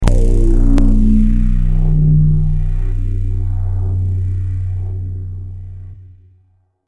جلوه های صوتی
دانلود صدای ربات 46 از ساعد نیوز با لینک مستقیم و کیفیت بالا